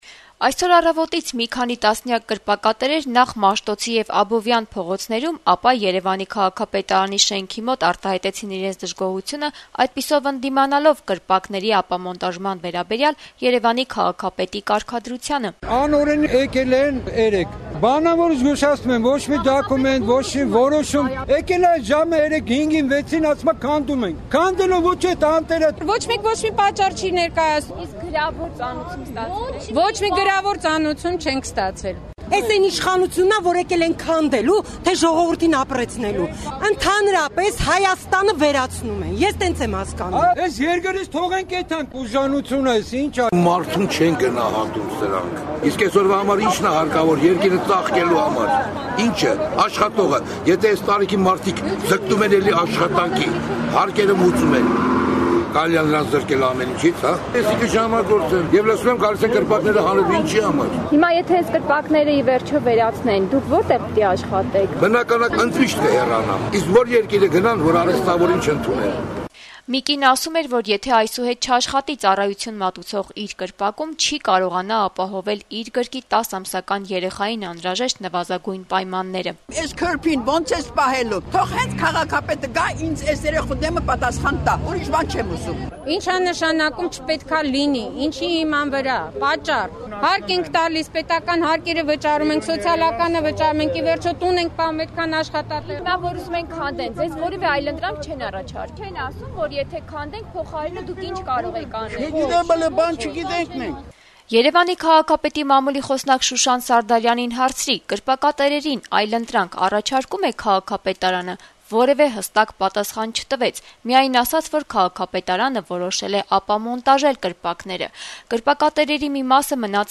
«Էս քյորփին ո՞նց ես պահելու… թող հենց քաղաքապետը գա ու ինձ այս երեխայի դիմաց պատասխան տա» , - «Ազատություն» ռադիոկայանի հետ զրույցում դժգոհեց բողոքող կրպակատերերից մեկը` միջին տարիքի մի կին` ցույց տալով գրկում պահած 10 ամսական երեխային: